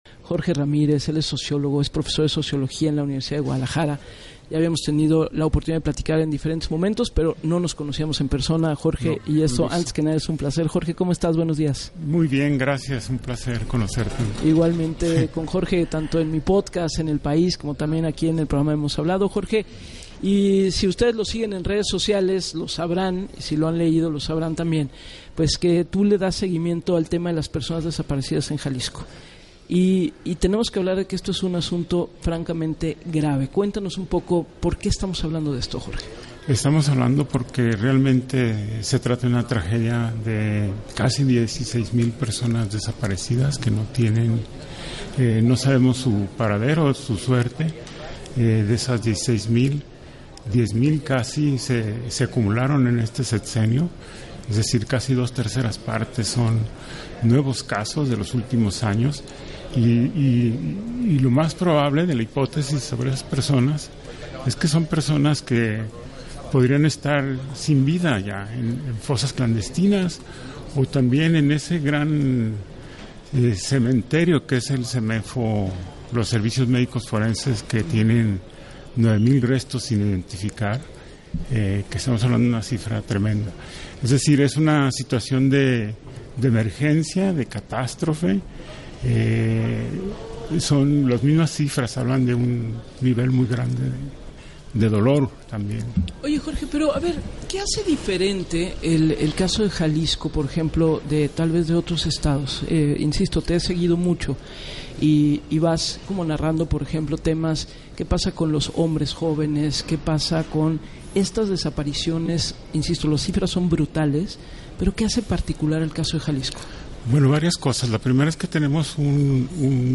En entrevista para “Así las Cosas” con Gabriela Warkentin, consideró que en Jalisco hay un grupo criminal asentado “muy poderoso y muy sanguinario”, el Cártel Jalisco Nueva Generación aunada dijo a “un gobierno -de Enrique Alfaro- que sistemáticamente negó el problema, lo subestimó”, que junto con la descoordinación con el gobierno federal, “generó un vacío de autoridad que aprovecharon los criminales que han estado seis años en impunidad sin ser tocados”.